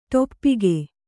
♪ ṭoppige